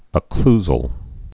(ə-klzəl, -səl)